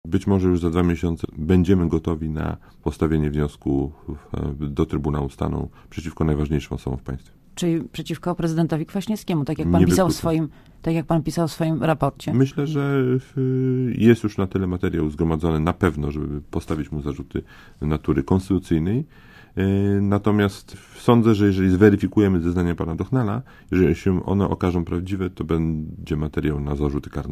Odmawiając stawienia się przed komisją śledczą ds. Orlenu skończył się prezydent Aleksander Kwaśniewski, a zaczął Aleksander K. – powiedział Roman Giertych z Ligi Polskich Rodzin, wtorkowy gość Radia ZET.
Mówi Roman Giertch